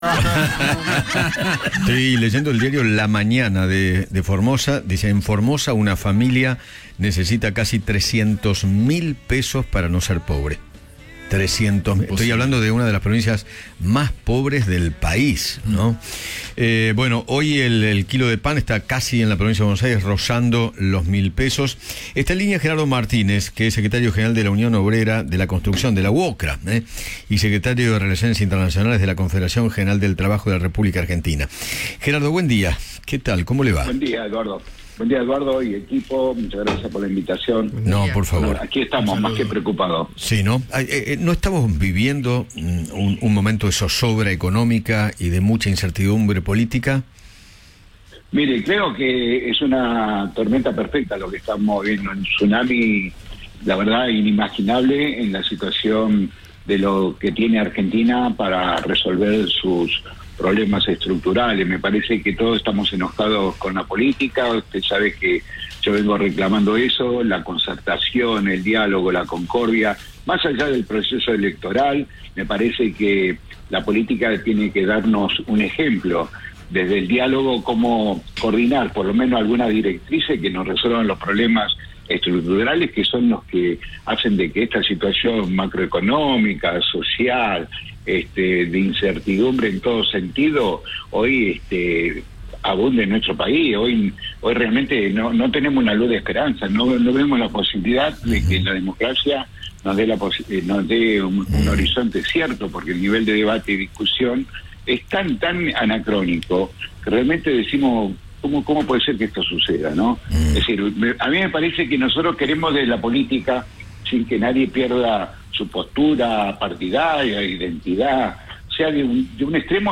Gerardo Martínez, Secretario General de UOCRA, dialogó con Eduardo Feinmann sobre la crisis económica y confirmó que pidieron la reapertura de paritarias.